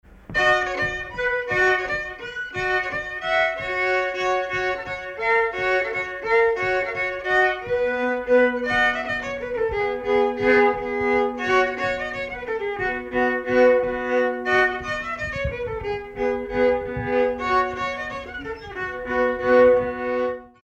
Villard-sur-Doron
danse : polka
circonstance : bal, dancerie
Pièce musicale inédite